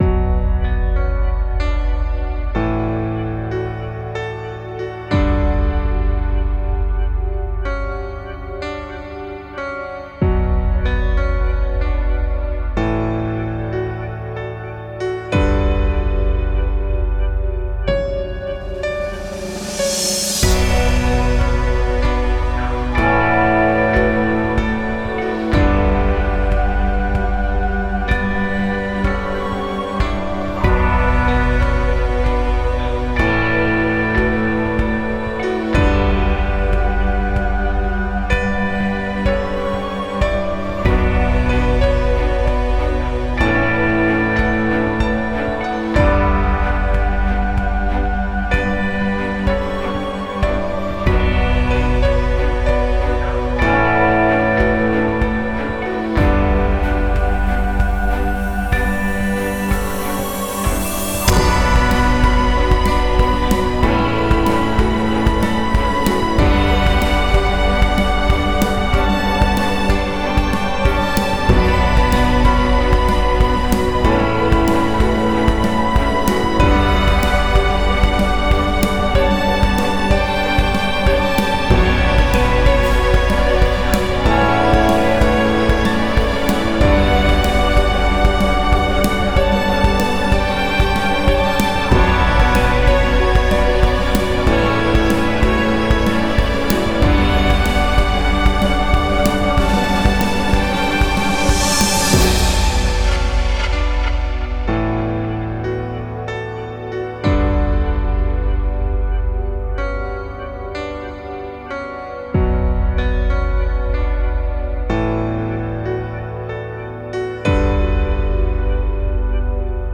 Tag: strings